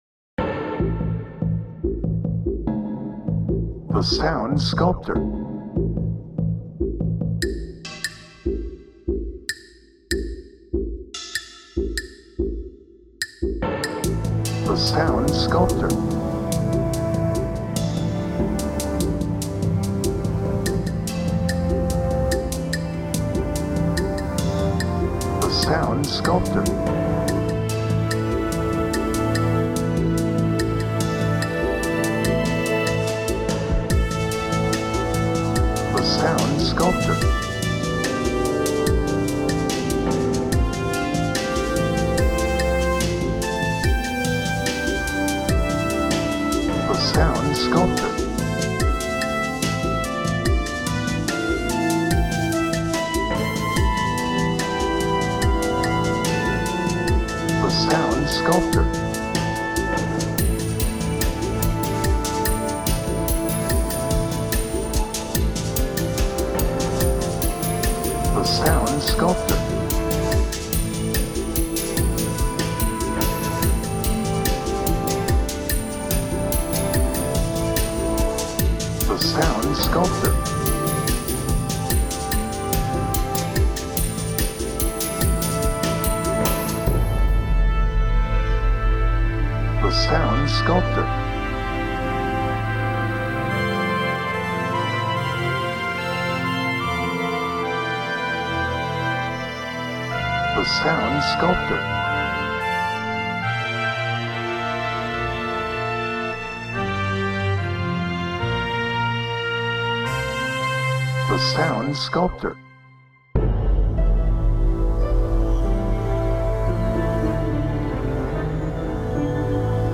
Brooding
Dark
Sad